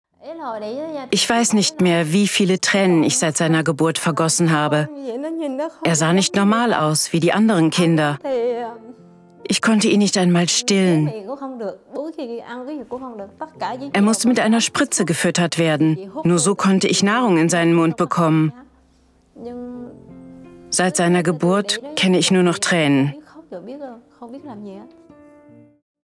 Voiceover (Demo)
warm, markant, tief, beruhigend, erzählerisch, vernünftig, psychologisch, seriös
Voice-Over-LKGS-m-Musik-1.mp3